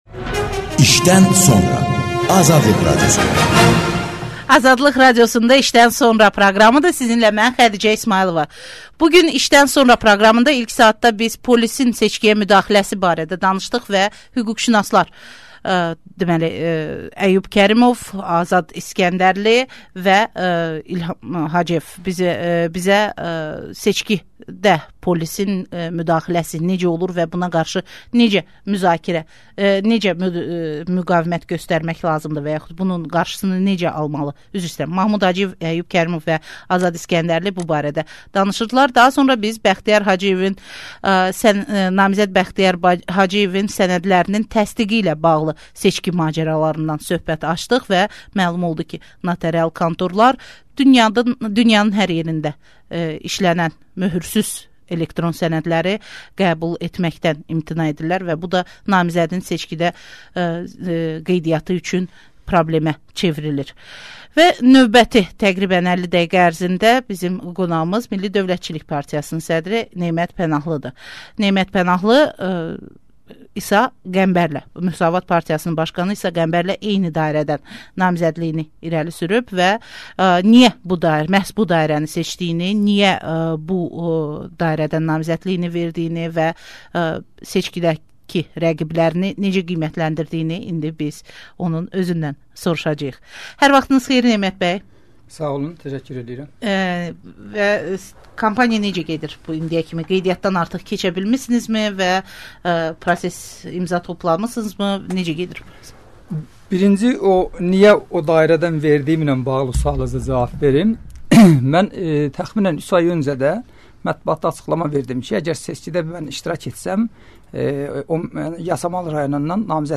Nemət Pənahlı ilə söhbət